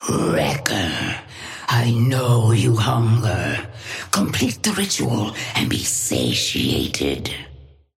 Sapphire Flame voice line - Wrecker.
Patron_female_ally_wrecker_start_01.mp3